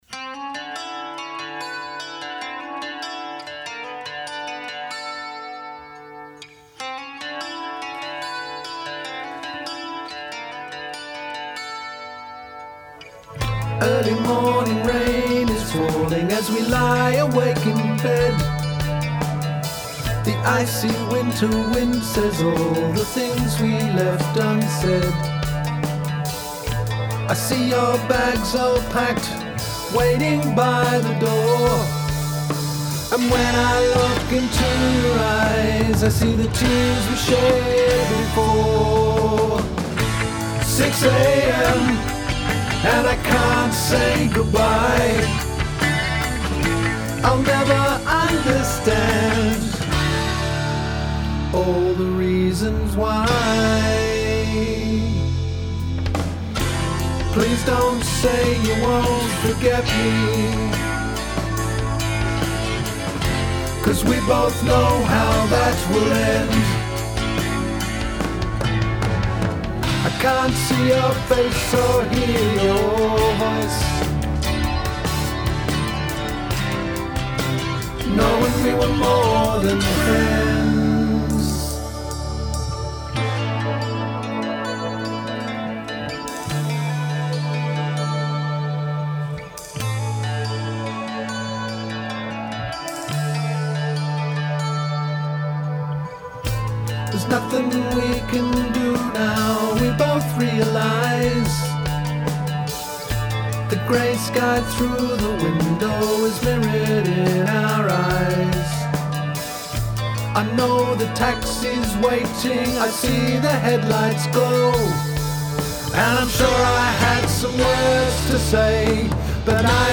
Vocals, Acoustic Guitar & Drums.
Vocals & Bass.
Keyboards.
Electric Guitar.
recorded in the 16 track studio at a radio station